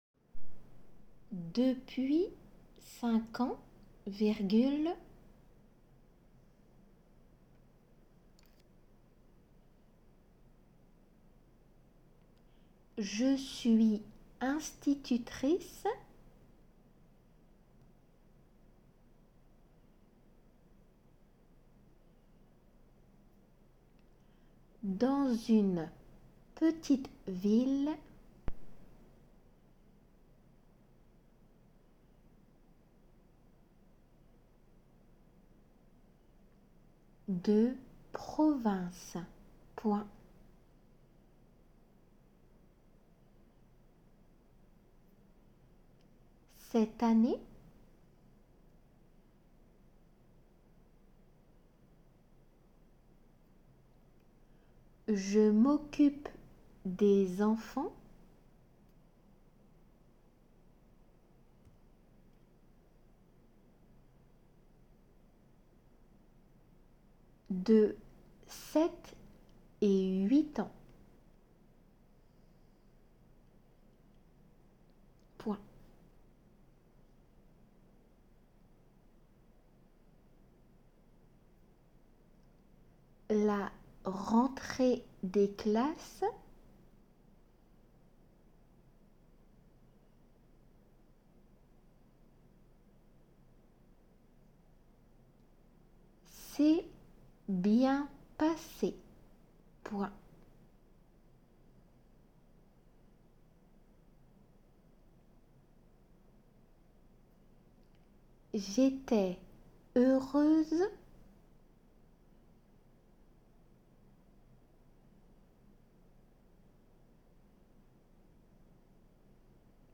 仏検　2級　デイクテ　音声ー秋３
このページではデクテ用の速度で一度だけ読まれます。